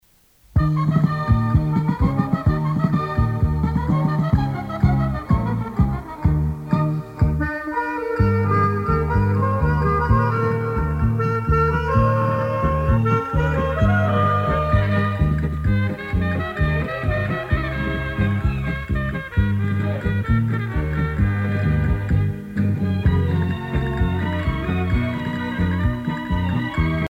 danse : paso musette
Pièce musicale éditée